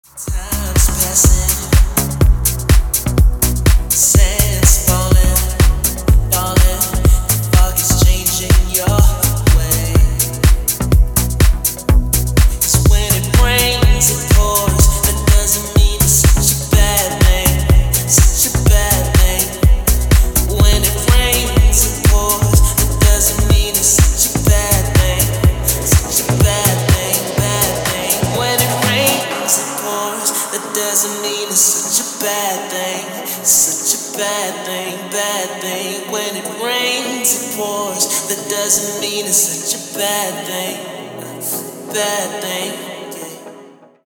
• Качество: 320, Stereo
мужской вокал
dance
Electronic
EDM
электронная музыка
спокойные
progressive house